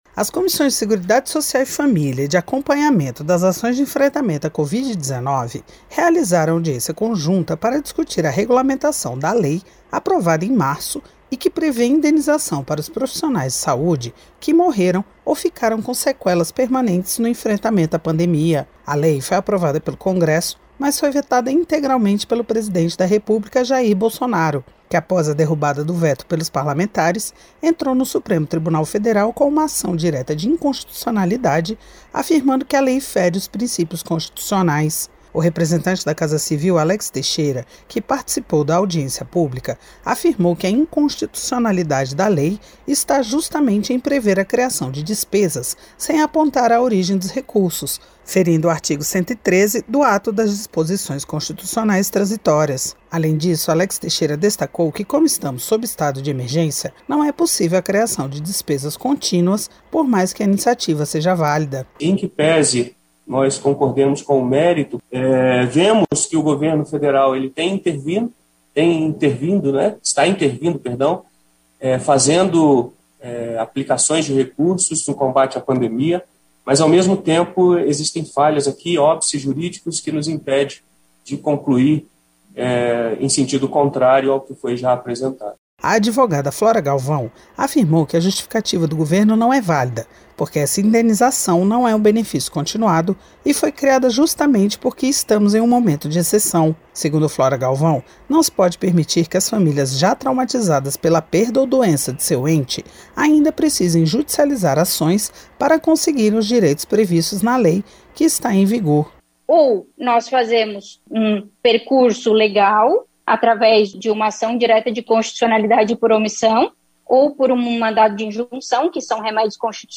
A reunião foi uma audiência pública conjunta das comissões de Seguridade Social e Família e de Acompanhamento das Ações de Enfrentamento à Covid-19.